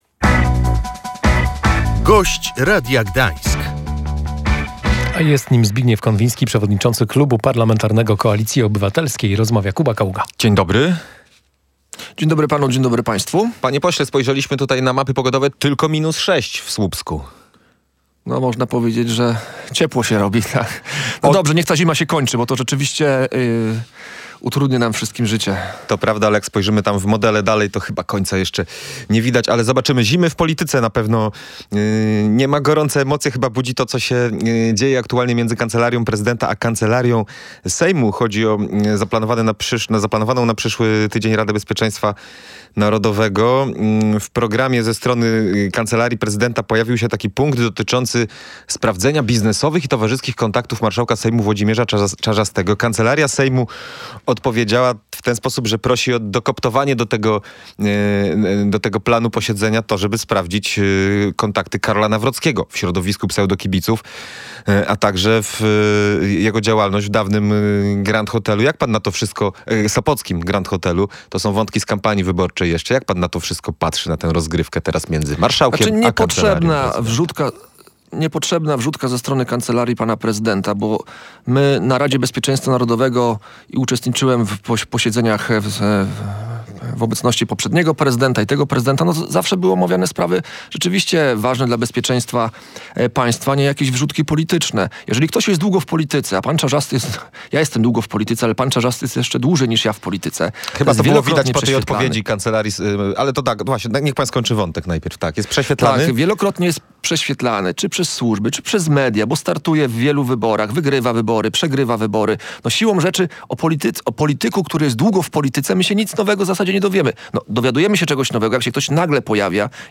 Prezydent powinien poważnie traktować Radę Bezpieczeństwa Narodowego – wskazywał gość Radia Gdańsk Zbigniew Konwiński, przewodniczący parlamentarnego klubu Koalicji Obywatelskiej.